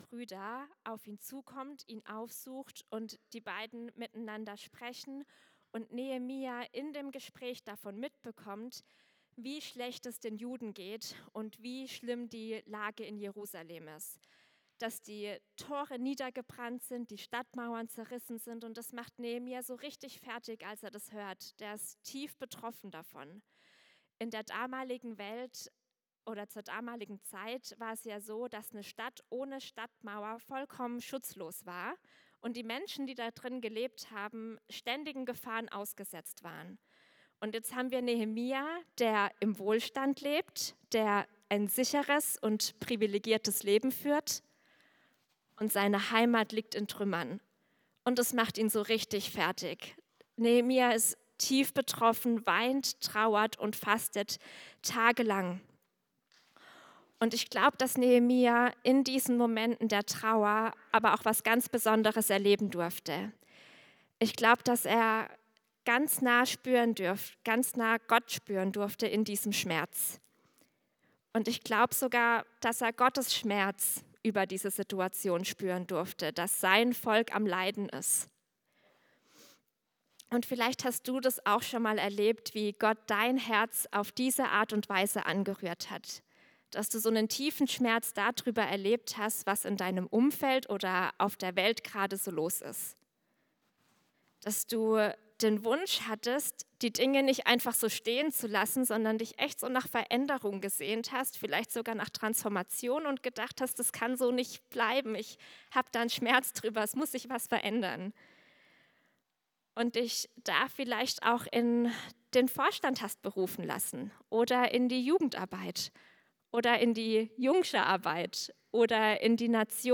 Themenbereich: Predigt
Format: Delegiertenversammlung
Diese Predigt von der Delegiertenversammlung 2026 des CVJM Badens lädt dazu ein, sich gemeinsam mit Nehemia auf den Weg zu machen hin zu einem echten Aufbruch mit Gott. Was bedeutet es, mutig Verantwortung zu übernehmen, Altes wieder aufzubauen und Neues zu wagen? Anhand der Geschichte Nehemias entdecken wir, wie Gott auch heute Menschen bewegt, die Welt zu verändern.